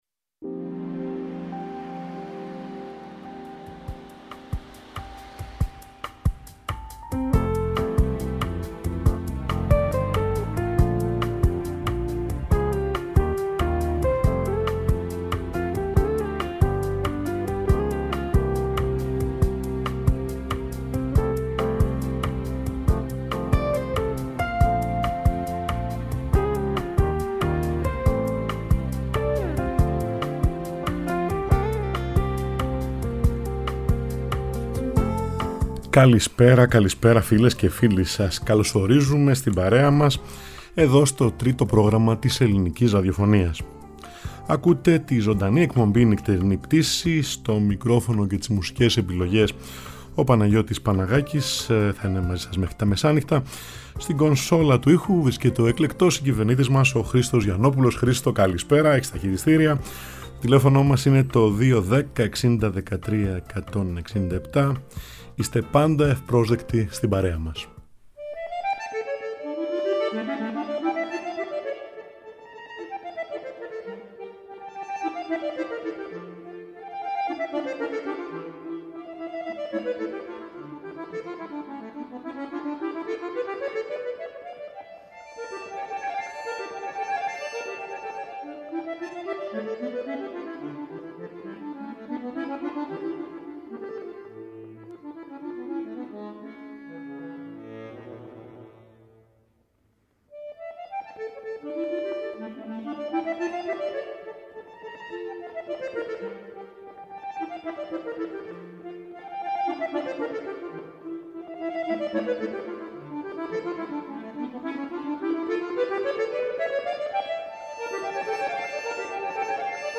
Διασκευές κλασικών έργων και jazz με «παριζιάνικο άρωμα»!